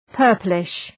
Προφορά
{‘pɜ:rplıʃ}